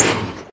minecraft / sounds / mob / blaze / hit4.ogg
hit4.ogg